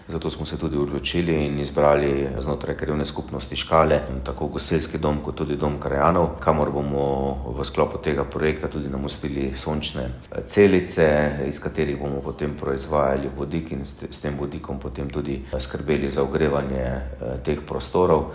Župan Mestne občine Velenje, Peter Dermol: